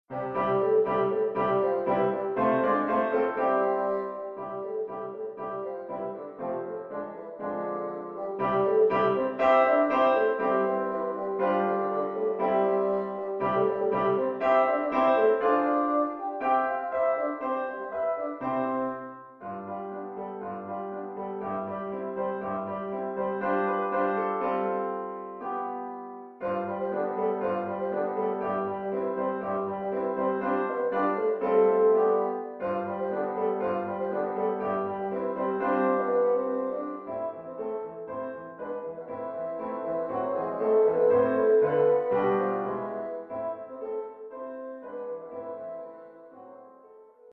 Oeuvre pour basson et piano.